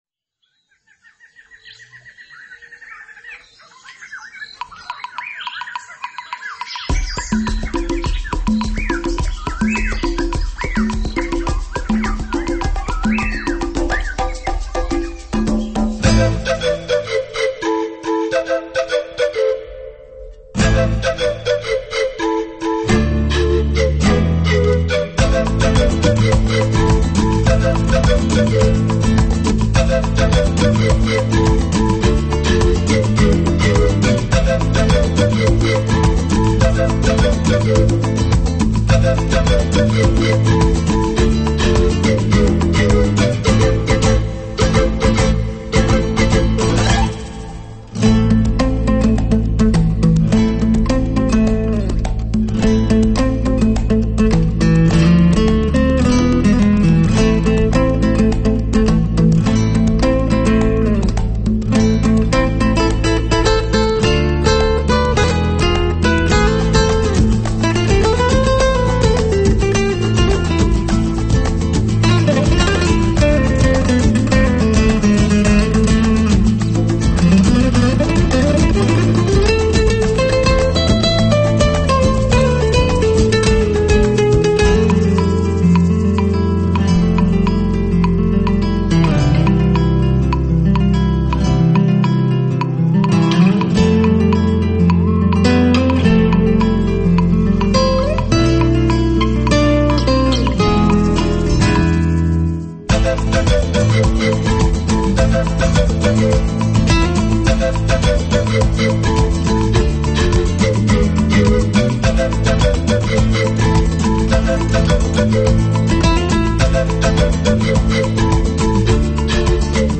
音乐风格：Nouveau Flamenco, New Age